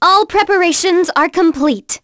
Artemia-vox-awk_01.wav